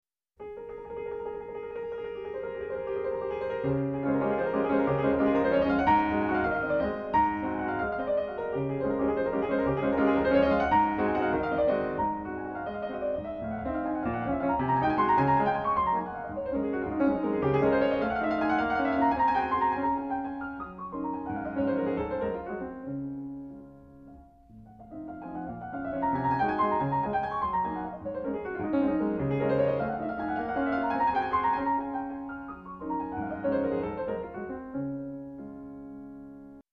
D flat major